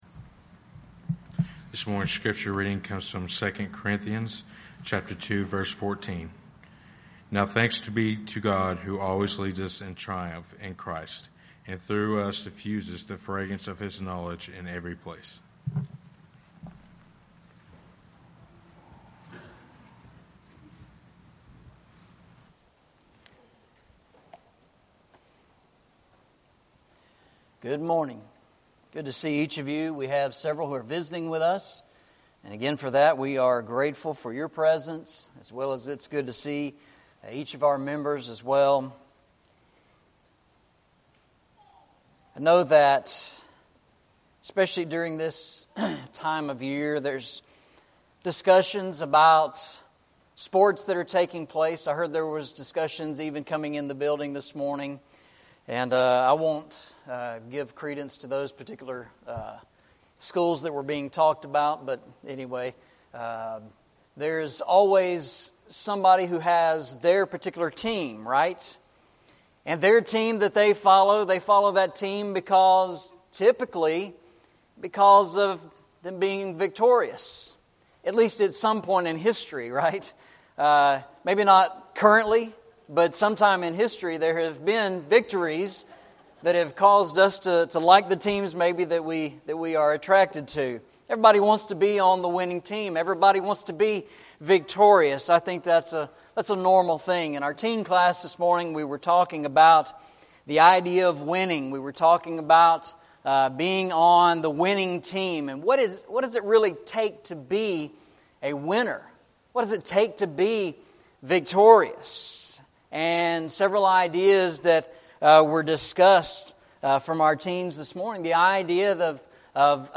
Eastside Sermons Passage: 2 Corinthians 2:14 Service Type: Sunday Morning